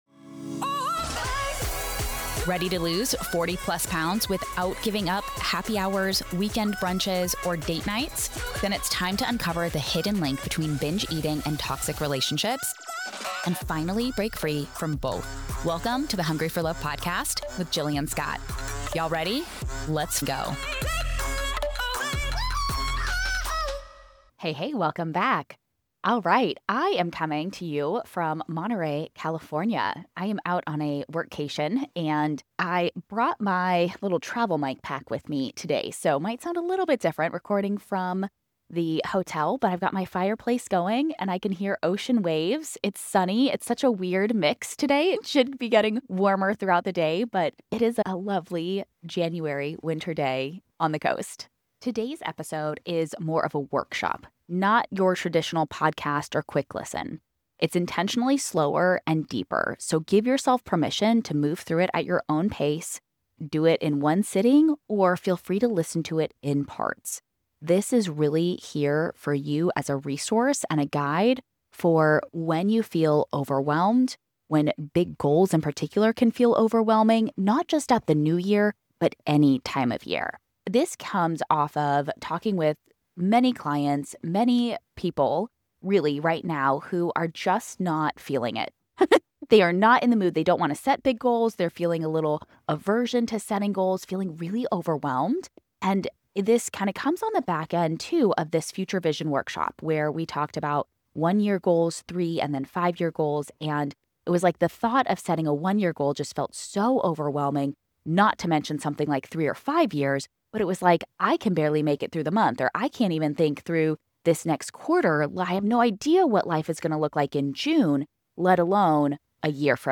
184. Why Big Goals Can Feel Overwhelming [Workshop]